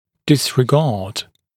[ˌdɪsrɪ’gɑːd][ˌдисри’га:д]игнорировать, пренебрегать; игнорирование, пренебрежение